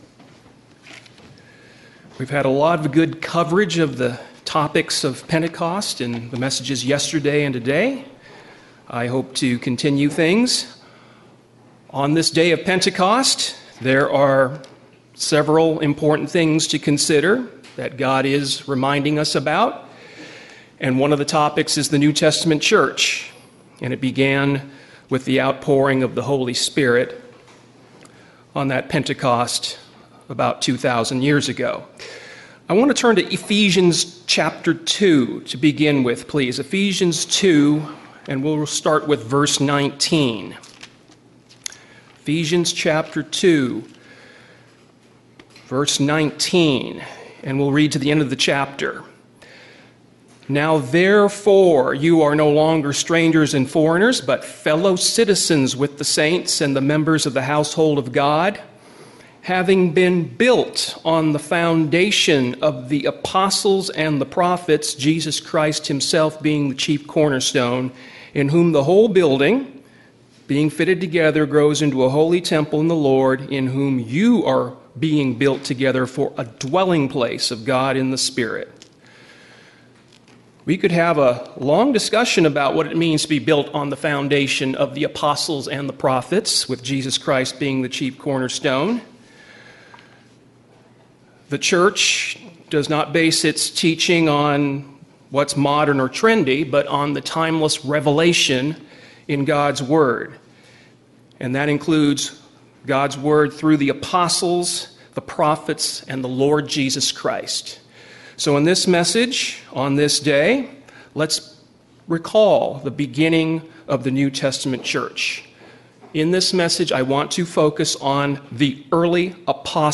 Sermons
Given in San Diego, CA Redlands, CA Las Vegas, NV